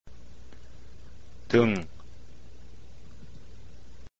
toong   upper back